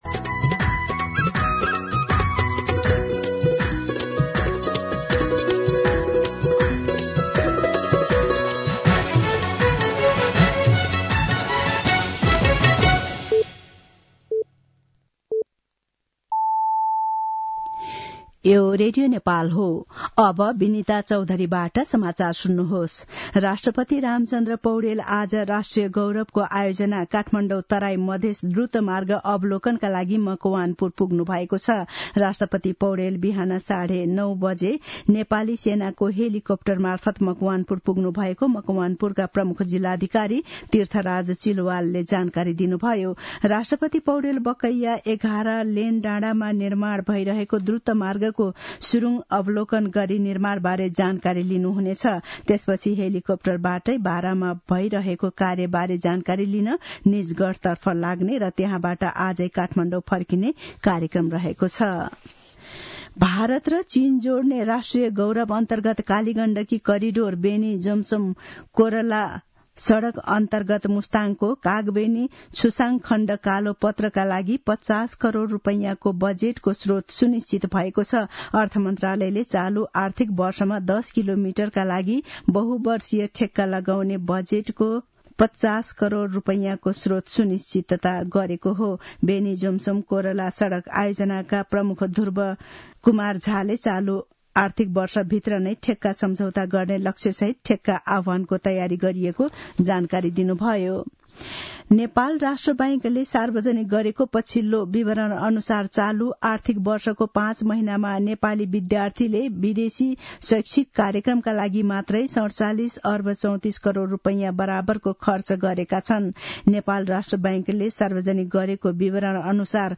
मध्यान्ह १२ बजेको नेपाली समाचार : ५ माघ , २०८१
12-pm-Nepali-News-10-4.mp3